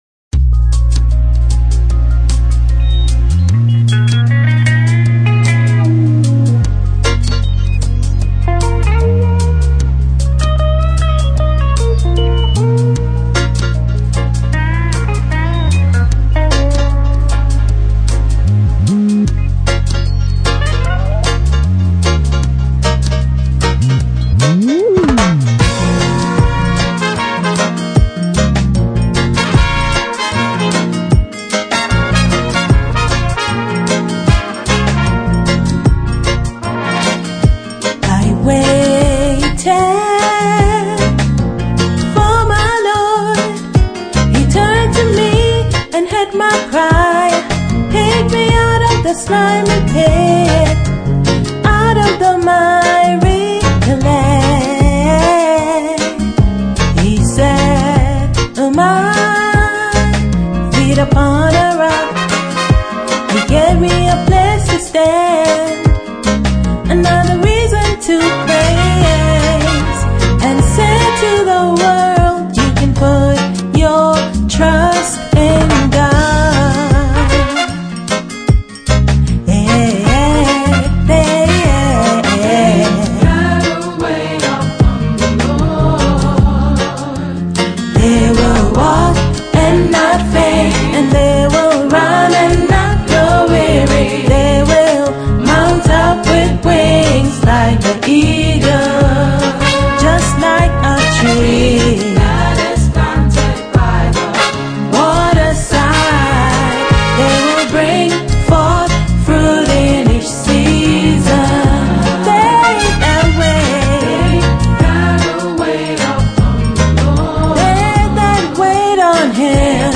gospel CD